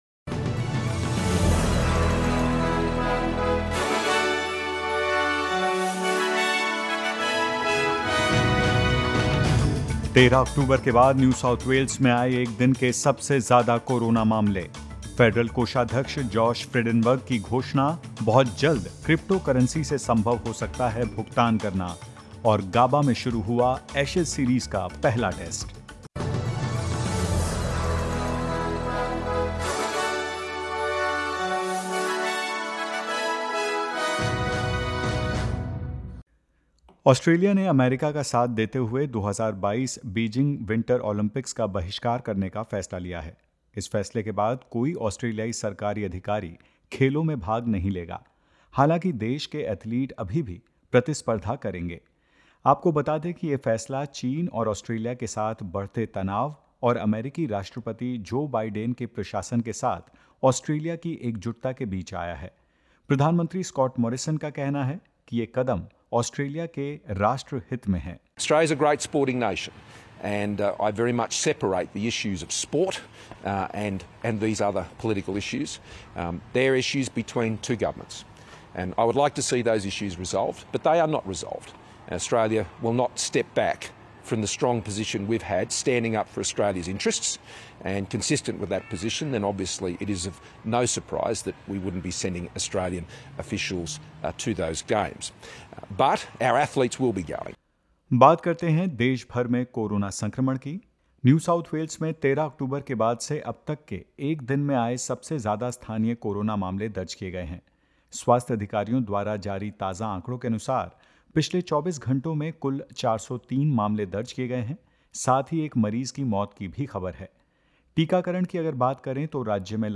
In this latest SBS Hindi news bulletin of Australia and India: Victoria and Queensland record their first cases of the Omicron variant of COVID-19; Australia joins the United States in its diplomatic boycott of the 2022 Beijing Winter Olympics and more.